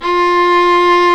Index of /90_sSampleCDs/Roland L-CD702/VOL-1/STR_Viola Solo/STR_Vla1 % + dyn